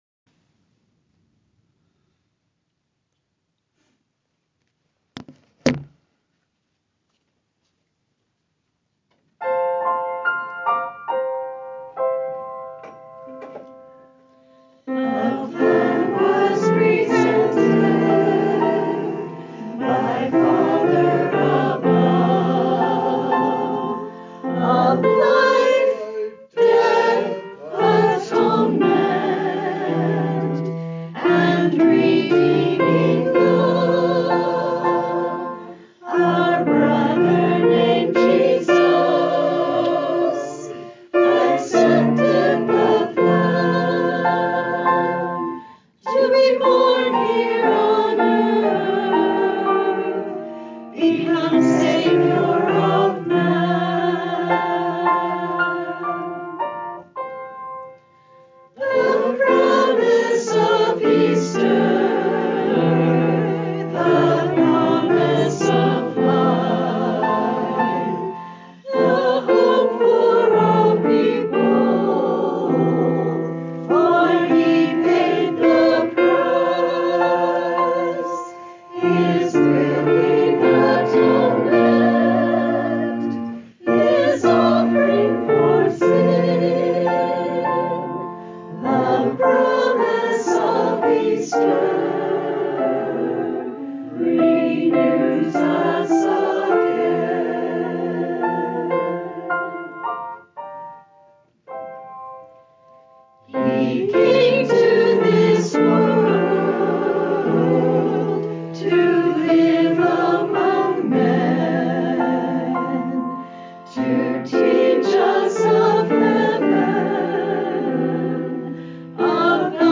The Mp3 is of a ward choir singing this song
Voicing/Instrumentation: SATB